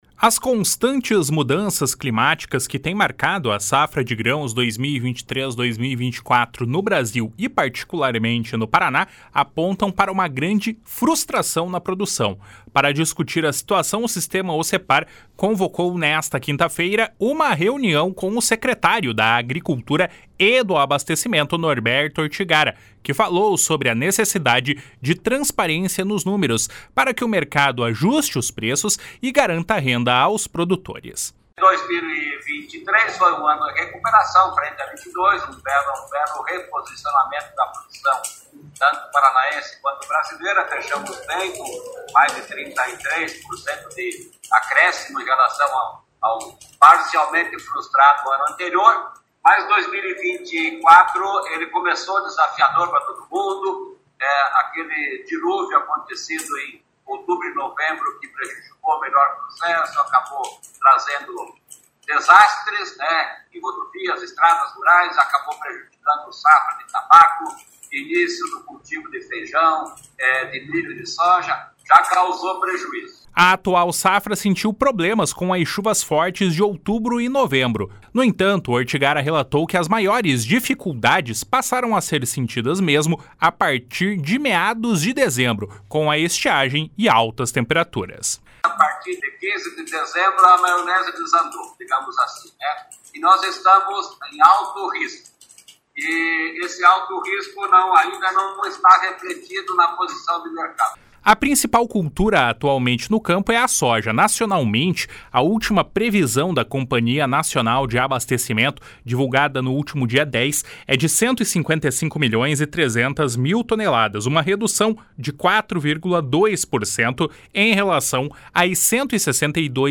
// SONORA NORBERTO ORTIGARA //
// SONORA NERI GELLER //